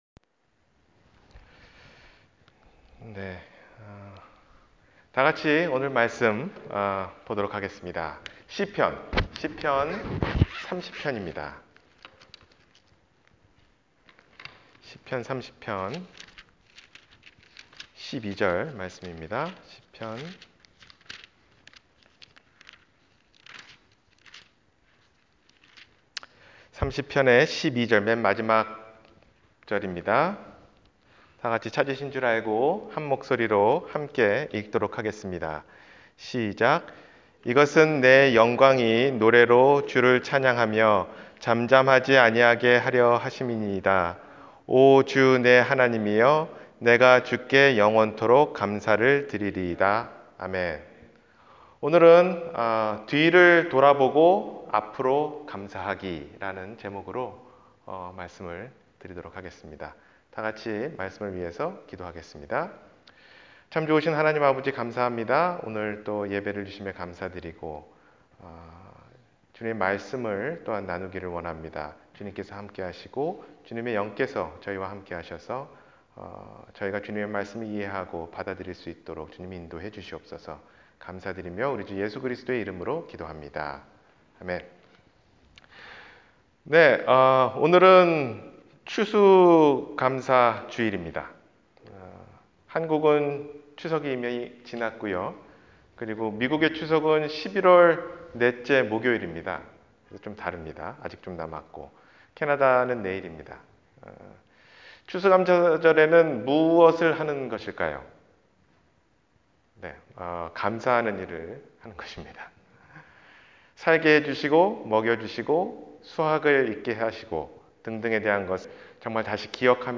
뒤를 돌아보고 앞으로 감사하기 – 주일설교 – 갈보리사랑침례교회